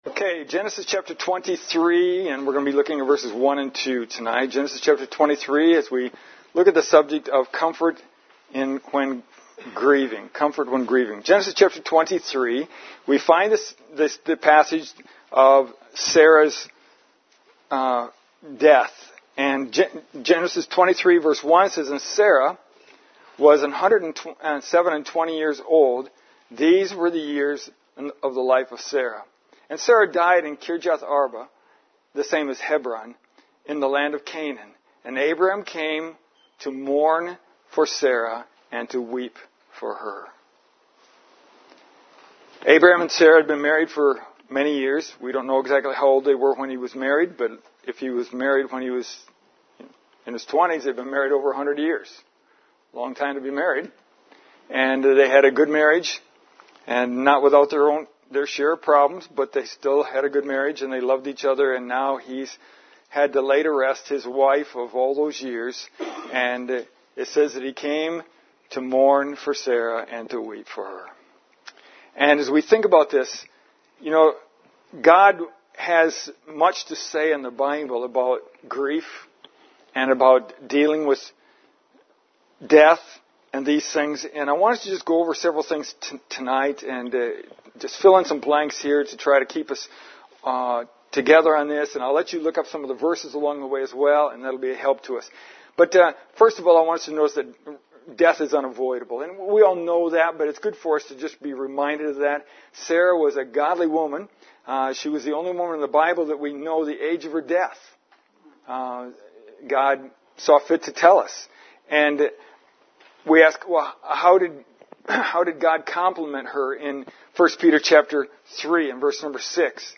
This is our Wednesday night Bible study.
Parts may be hard to hear because of the interaction I have with our congregation.